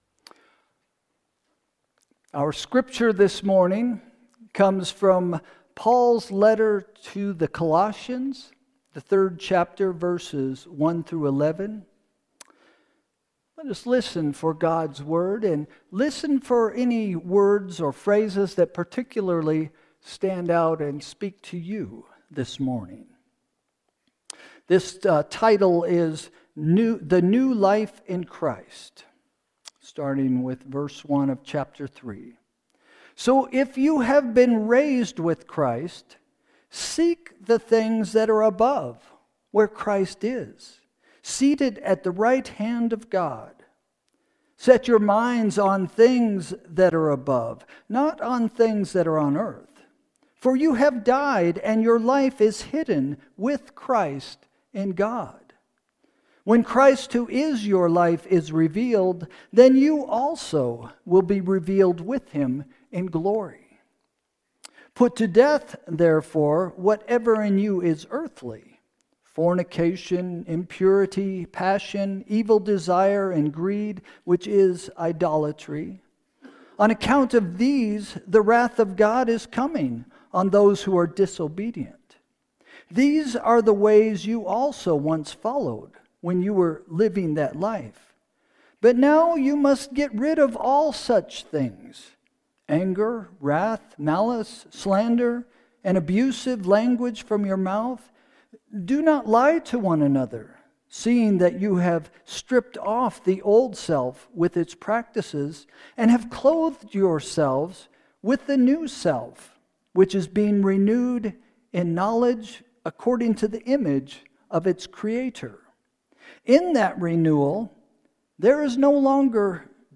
Sermon – May 18, 2025 – “Earthly Hope”